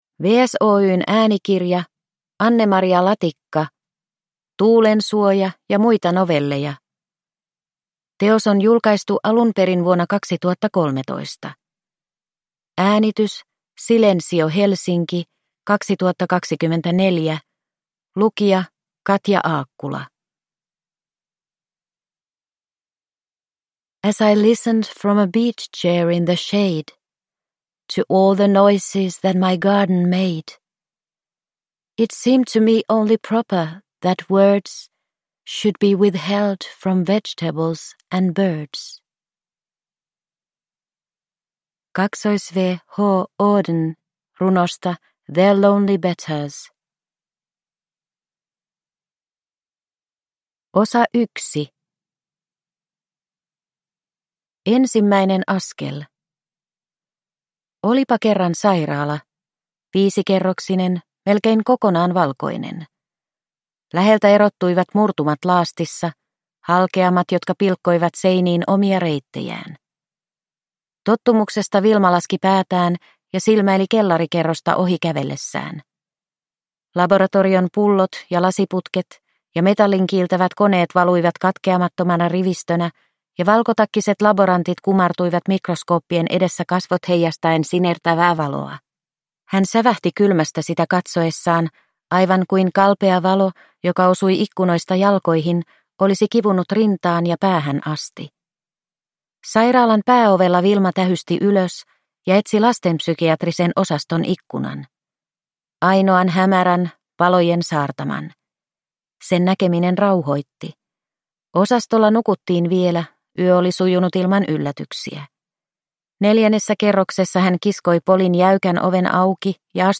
Tuulensuoja ja muita novelleja – Ljudbok